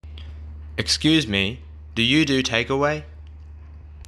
ネィティヴの音声を録音したので、雰囲気を感じて下さいね。